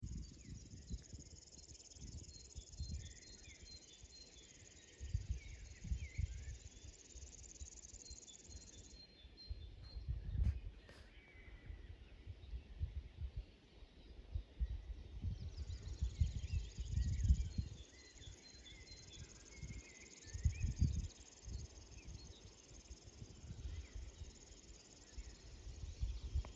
Upes ķauķis, Locustella fluviatilis
Administratīvā teritorijaValkas novads
StatussDzied ligzdošanai piemērotā biotopā (D)